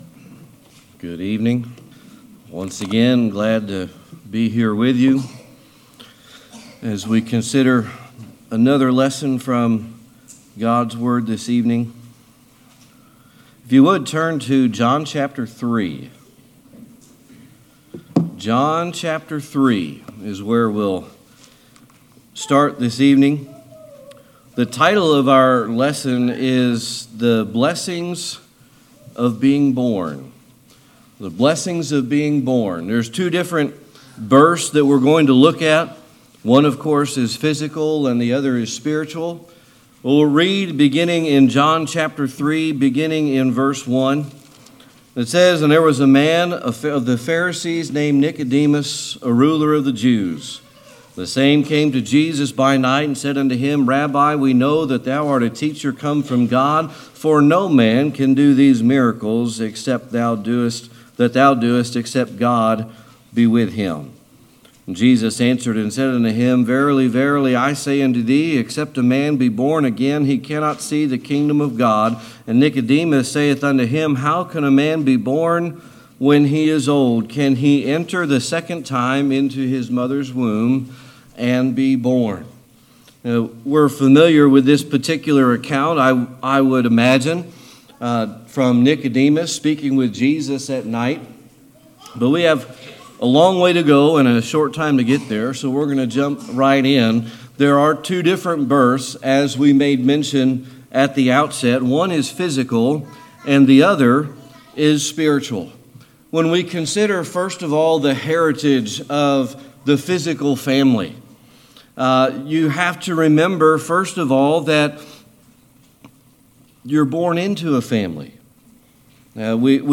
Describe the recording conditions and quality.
John 3:1-4 Service Type: Sunday Evening Worship John chapter 3 is where we will start.